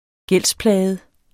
Udtale [ -ˌplæːjəð ]